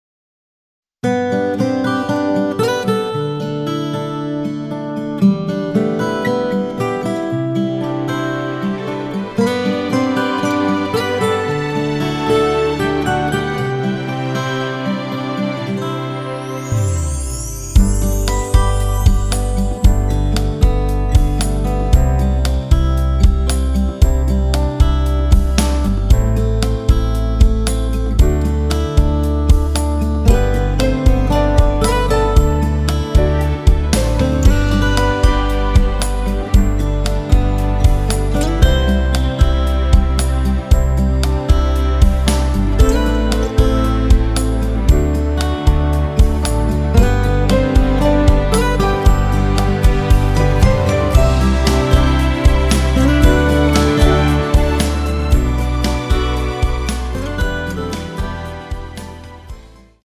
[공식 음원 MR]
앞부분30초, 뒷부분30초씩 편집해서 올려 드리고 있습니다.